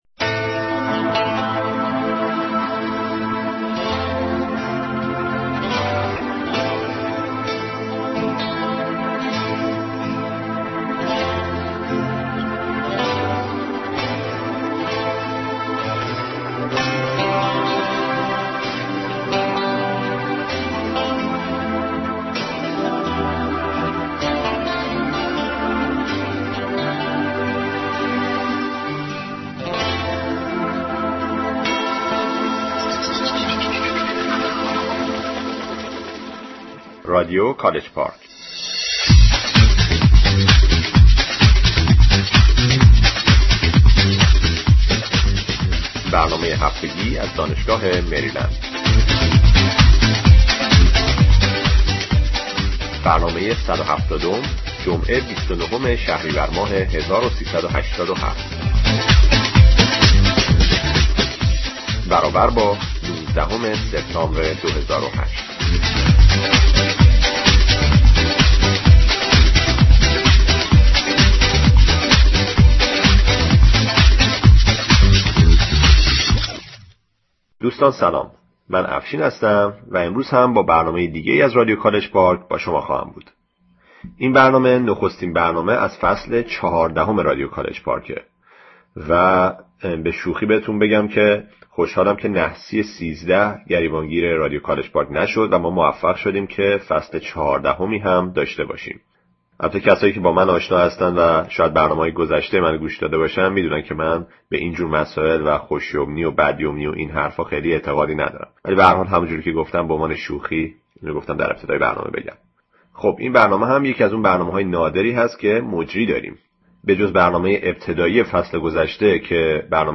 صدای خوبی هم برای گویندگی داری.